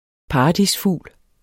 Udtale [ ˈpɑːɑdis- ]